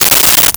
Coins In Hand 01
Coins in Hand 01.wav